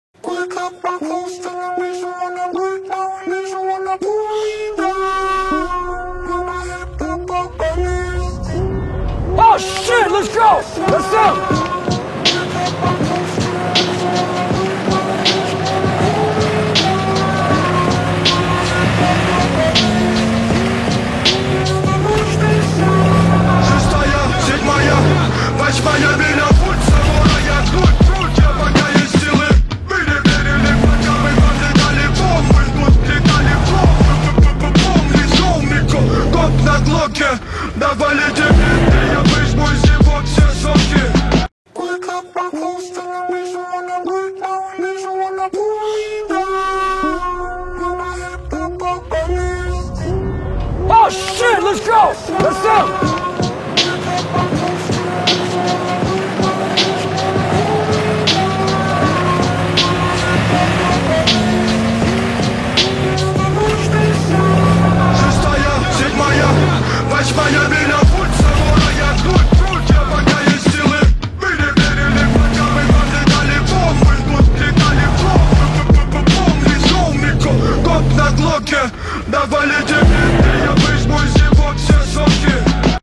Рэп, Хип-хоп